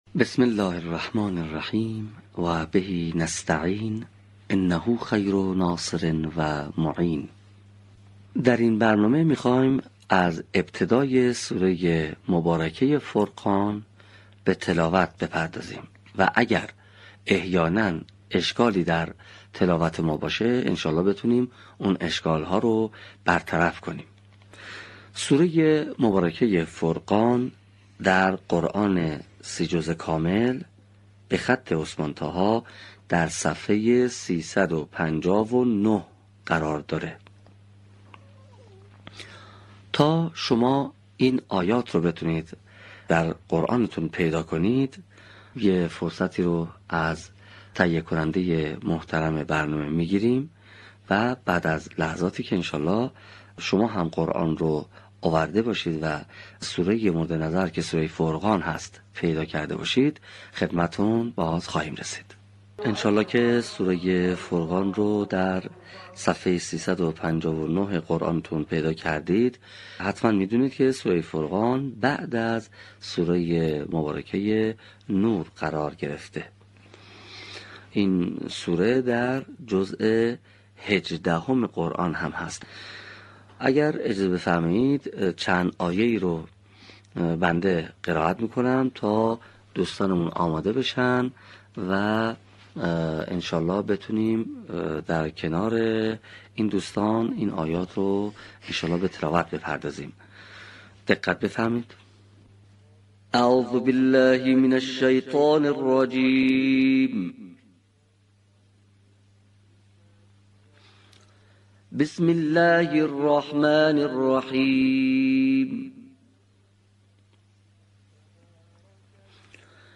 صوت | آموزش قرائت آیات ۱ تا ۵ سوره فرقان